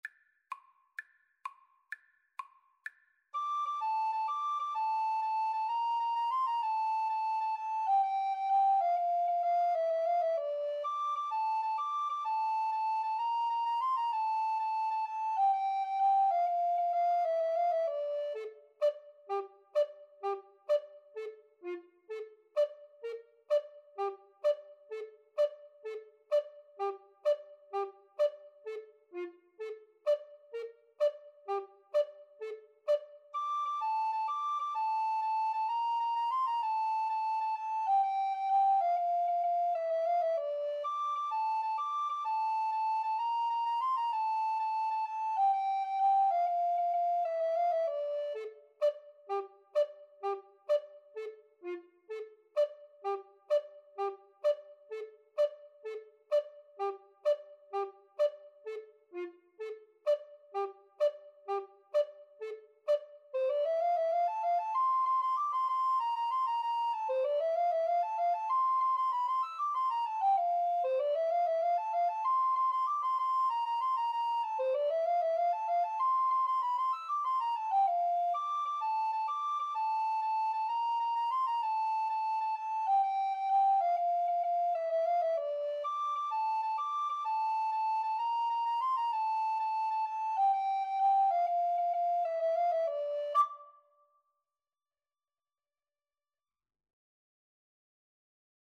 Free Sheet music for Alto Recorder Duet
D minor (Sounding Pitch) (View more D minor Music for Alto Recorder Duet )
6/8 (View more 6/8 Music)